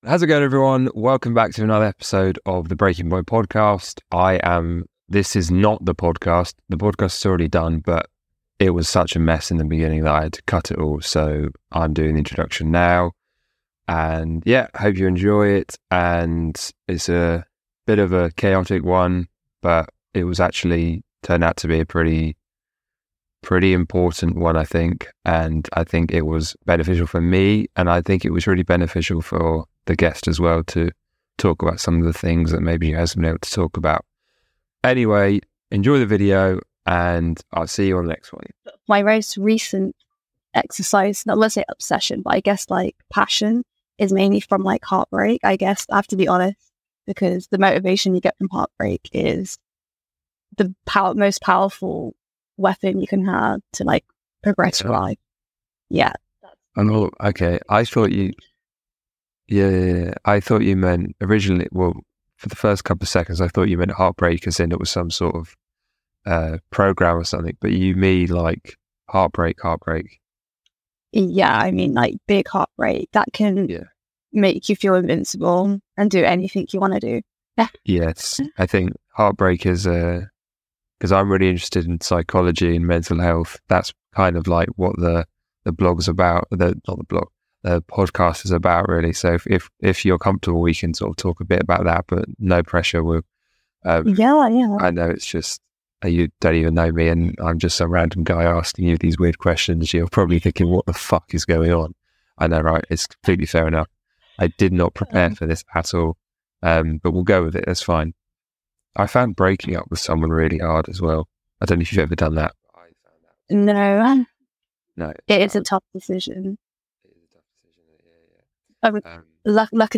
our conversation featured heavy topics, such as heartbreak, dating and social media. As always there was an underlying reference to her mental health and the mental health of the younger generations.